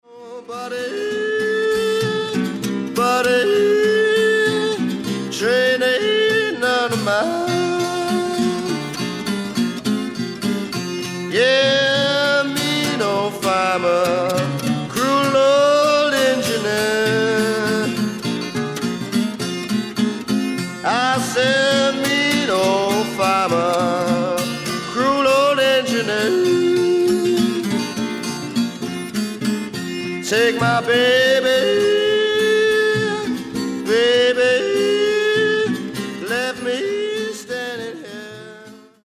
BLUES ROCK / COUNTRY BLUES